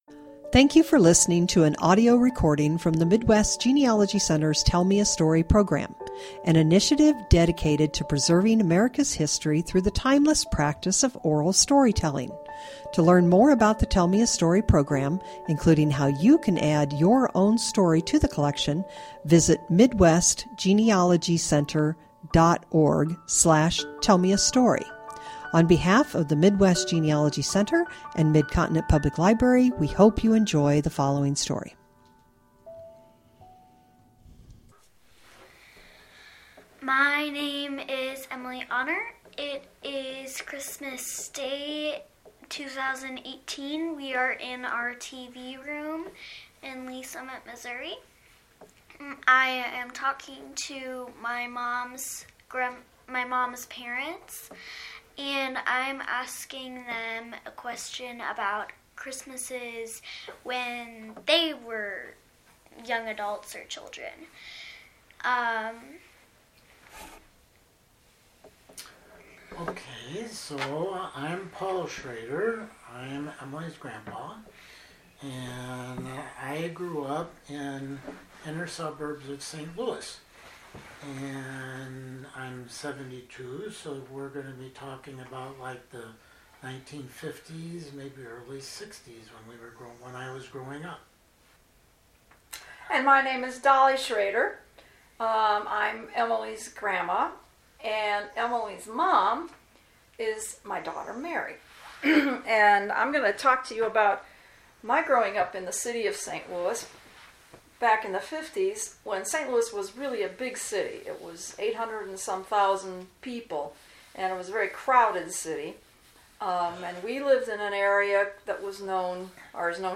Subject Oral History